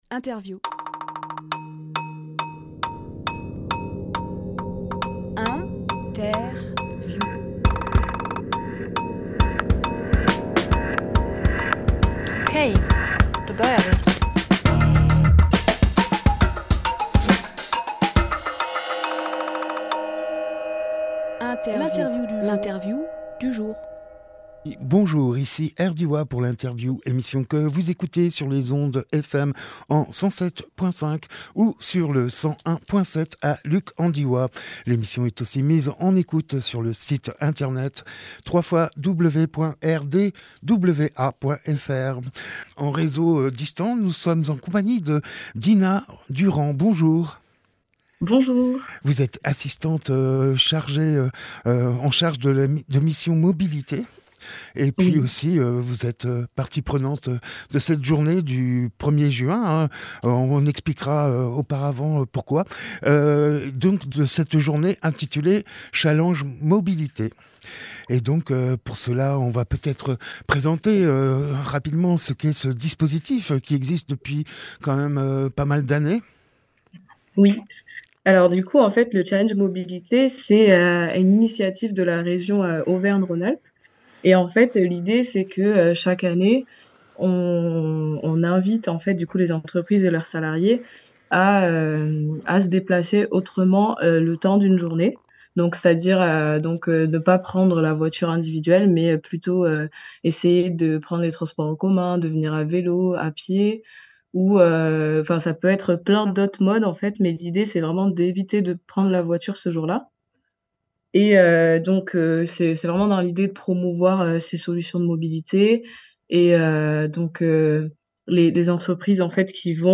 Emission - Interview Le Challenge mobilité 2023 Publié le 1 juin 2023 Partager sur…
01.06.23 Lieu : Studio RDWA Durée